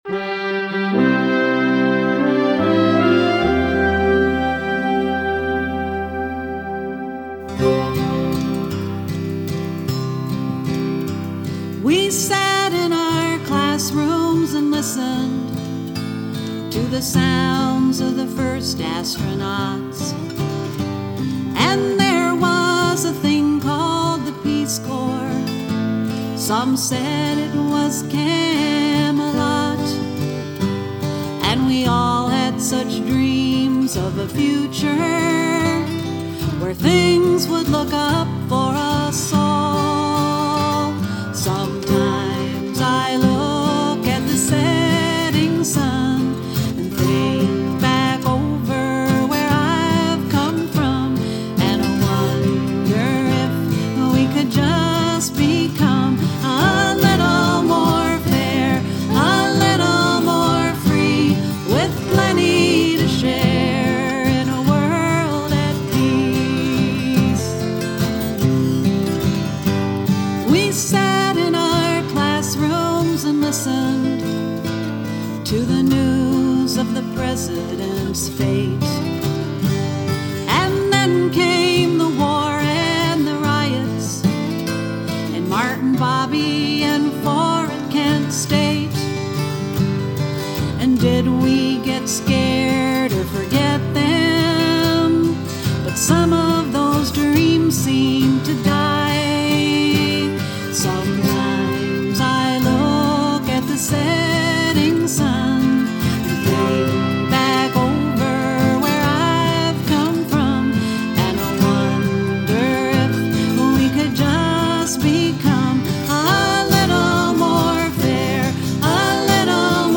" and the memorable folk anthem